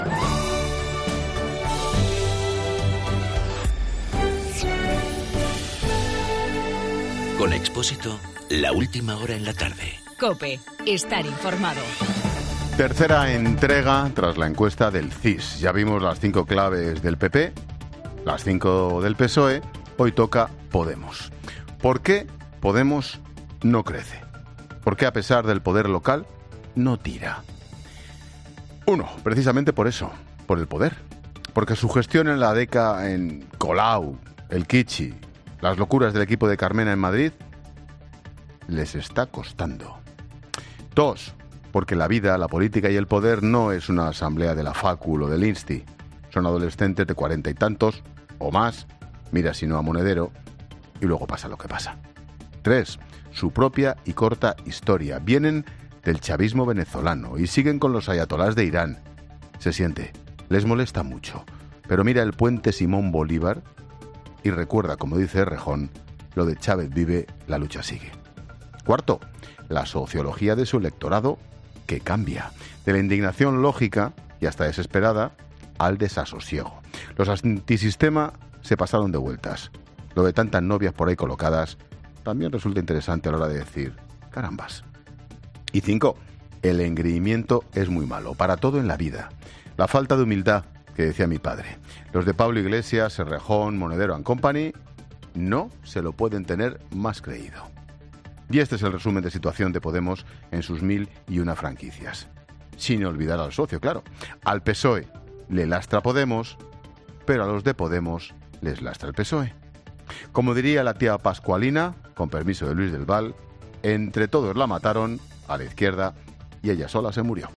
Monólogo de Expósito
El comentario de Ángel Expósito sobre Podemos y su subida en las encuestas.